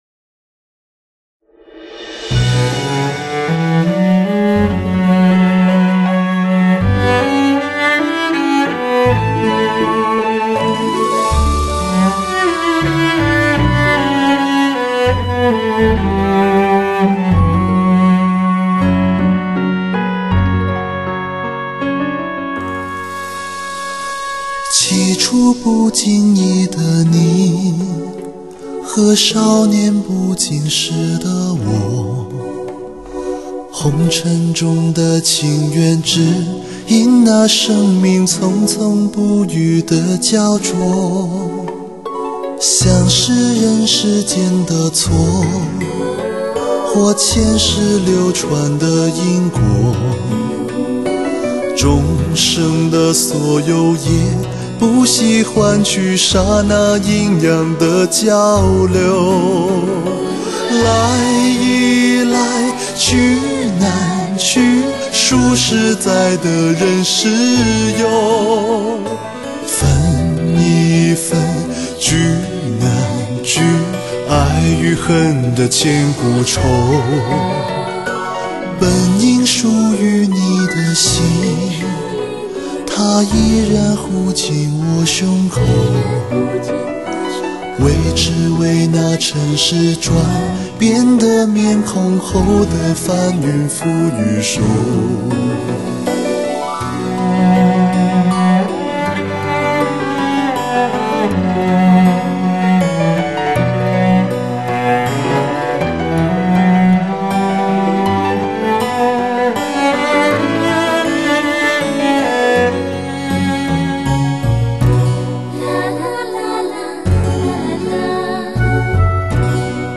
焰火般的声音，一段段华丽的听觉之旅，绝佳声色回味！
高亢的嗓音，投入的表演，让你得到最完美的身心享受。